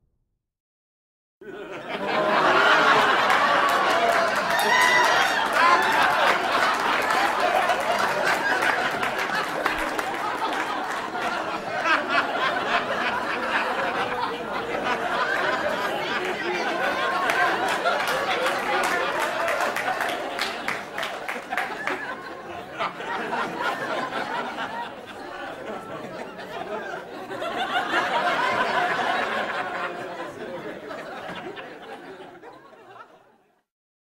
دانلود صدای خندیدن جمعیت و مردم 4 از ساعد نیوز با لینک مستقیم و کیفیت بالا
جلوه های صوتی